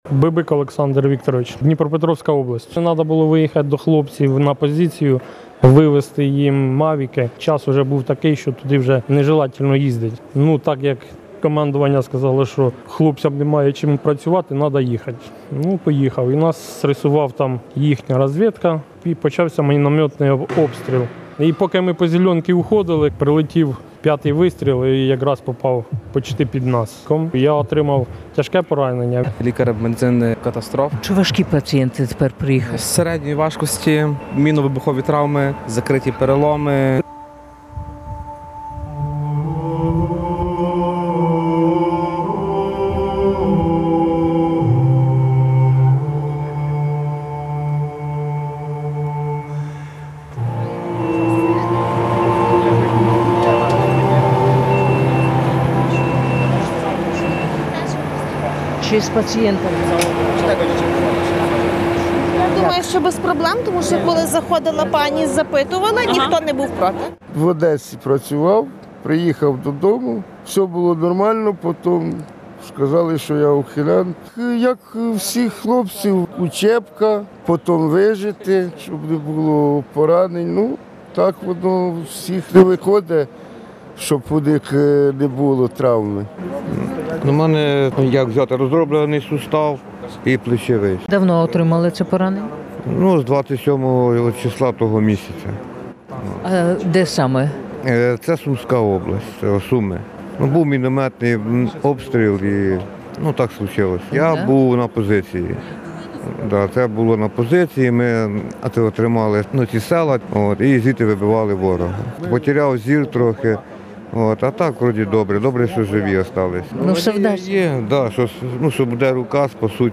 Більше трьох років в Ясьонці діє медичнийй хаб, де приймають травмованих мюілізованих і лікарі, медратівники та волонтери підготовляють їх до дальшої дороги на лікування за кордон. Послухайте репортаж ” Люди титани”